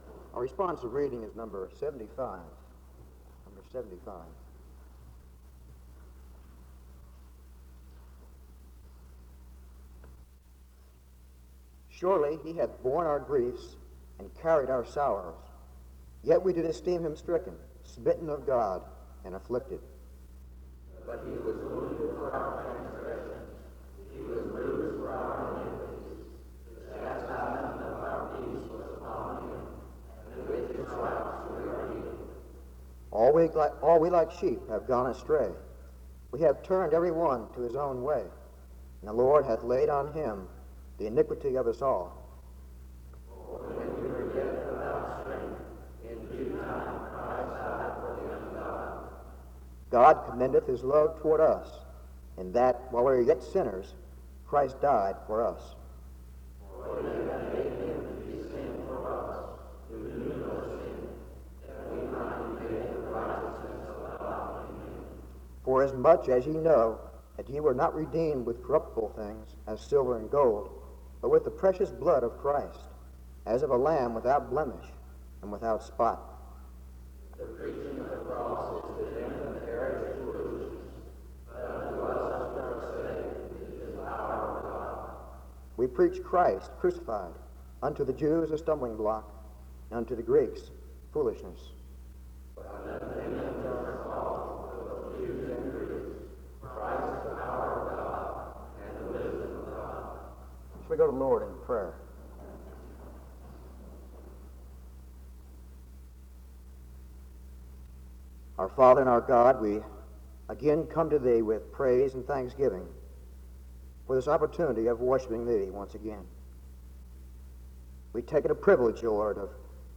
SEBTS Chapel
He explains how God came down to reconcile relationships by coming in humility and by dying on the cross (11:06-18:07). He concludes by challenging his audience to participate in the ministry of reconciliation by living out their faith in obedience (18:08-24:35). He closes in prayer (24:36-25:18).